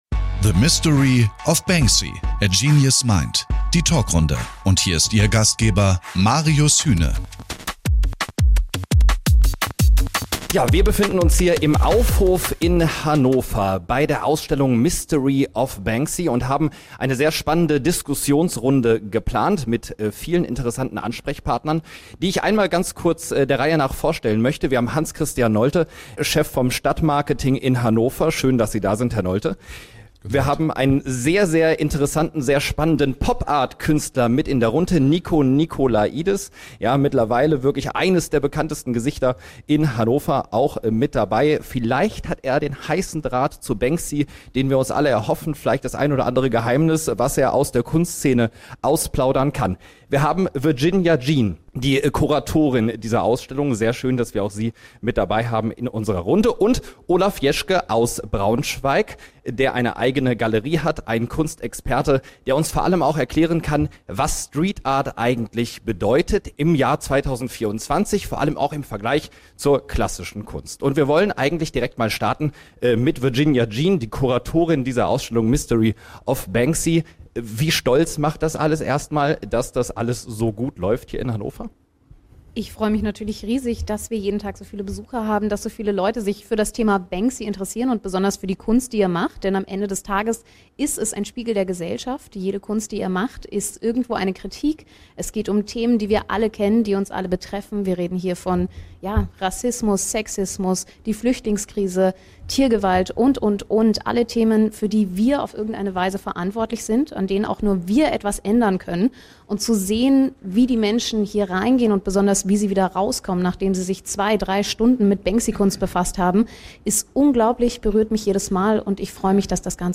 Die Redaktion wagt einen mutigen Formatbruch: Rund eine Stunde Kultur mit ausgewiesenen Expertinnen und Experten. Die lebendige Talkrunde liefert neue, überraschende Erkenntnisse und Hintergrundwissen gepaart mit einer ausgezeichneten Moderation.